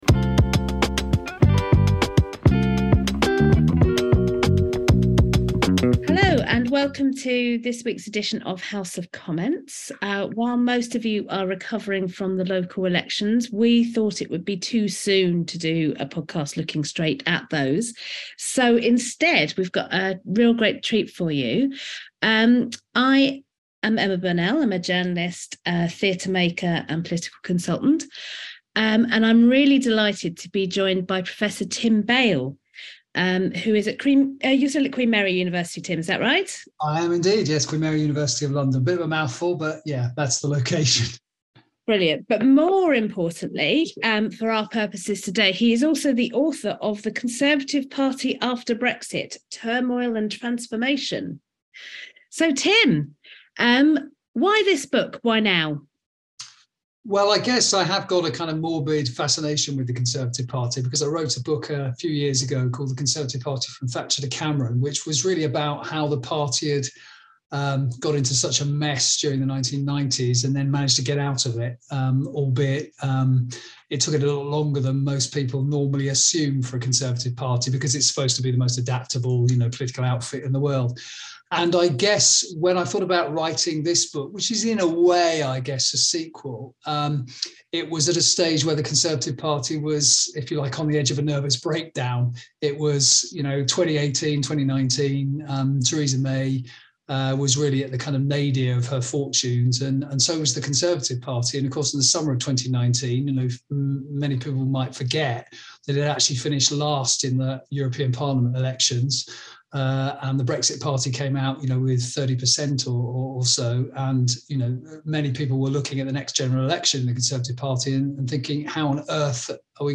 In another special interview